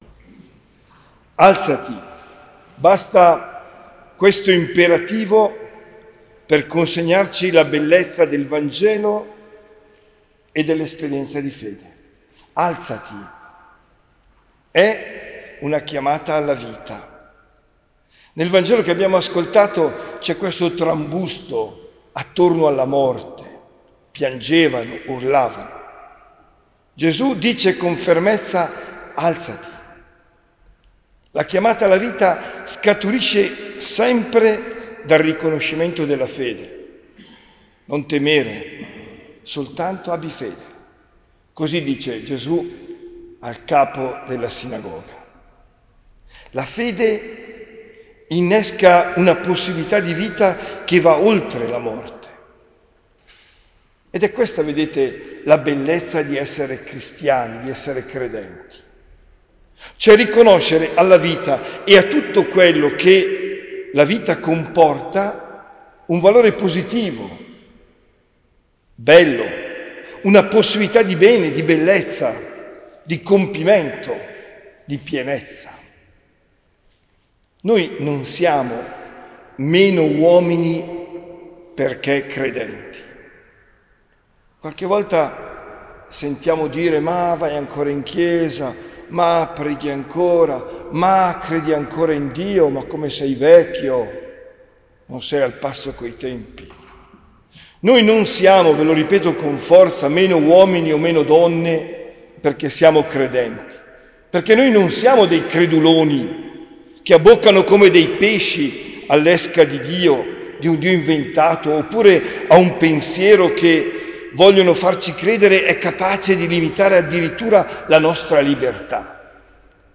OMELIA DEL 30 GIUGNO 2024